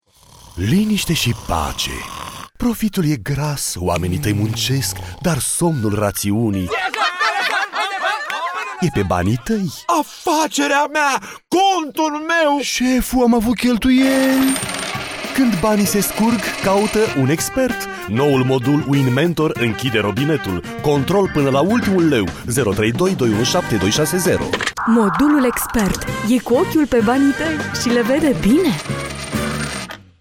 SPOTURI RADIO